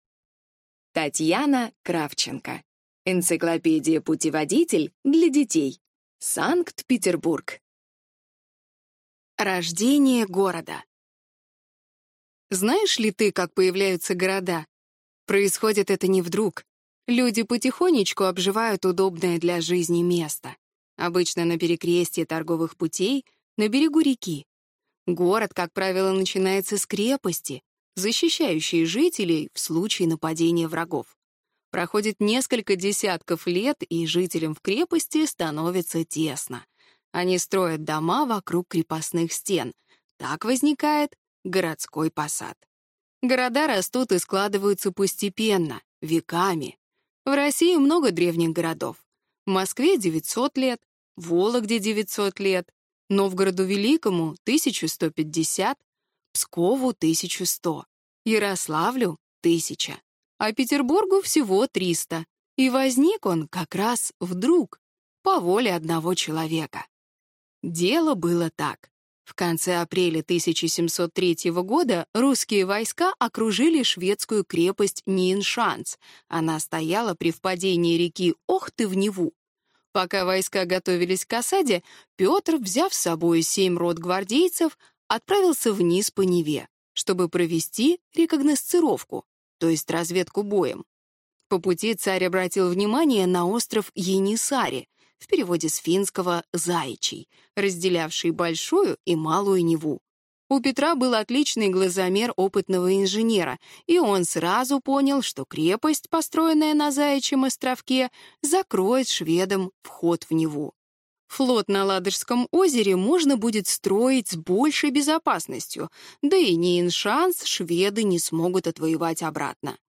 Аудиокнига Санкт-Петербург | Библиотека аудиокниг
Прослушать и бесплатно скачать фрагмент аудиокниги